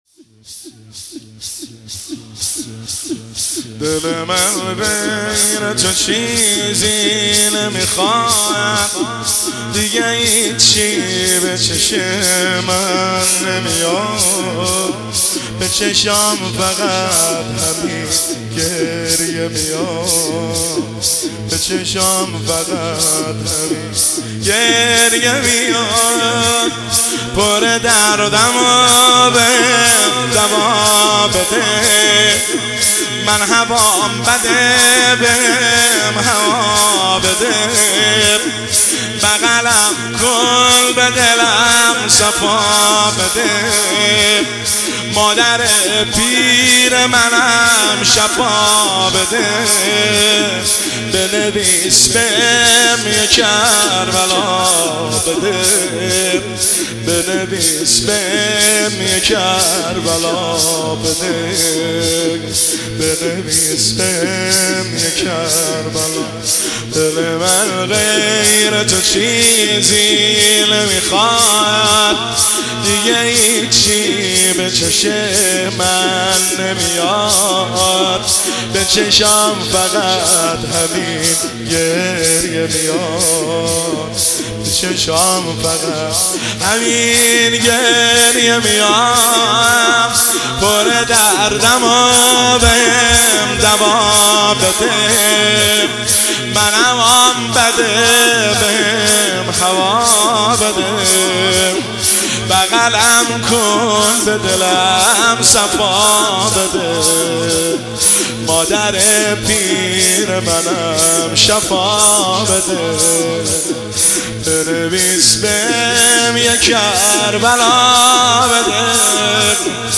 شور شب دوم محرم الحرام 1404